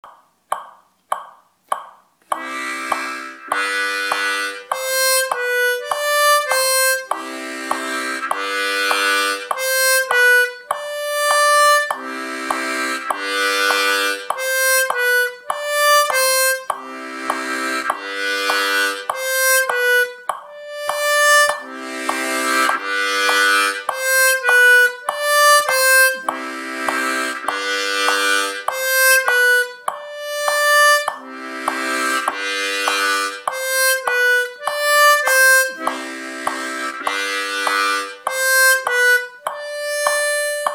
| САМОУЧИТЕЛЬ ИГРЫ НА ГУБНОЙ ГАРМОШКЕ
ИСПОЛЬЗОВАНИЕ ОДИНОЧНЫХ НОТ В РИТМАХ
Например вот такой ритм, куда мы «вплели» несколько нот.